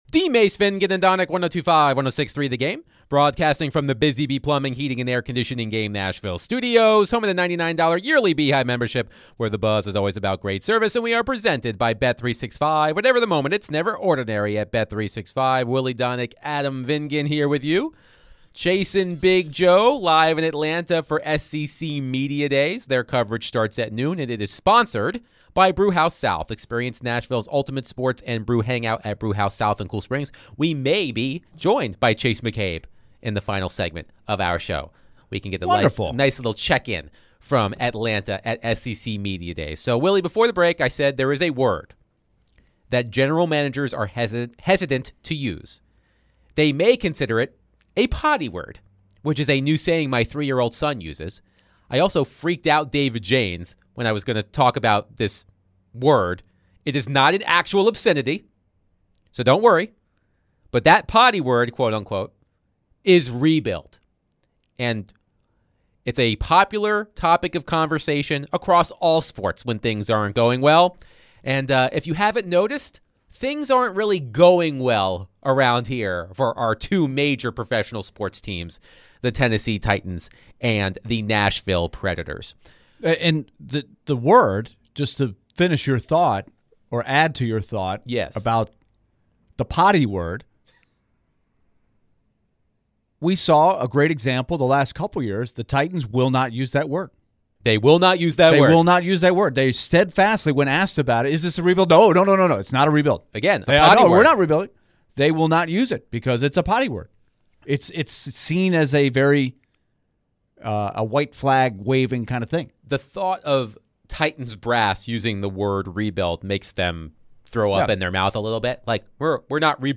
In the final hour of DVD, the guys discuss whether rebuilds work in the NHL or not. They got reactions from listeners and callers. to end the hour